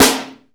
SNARE NICE.wav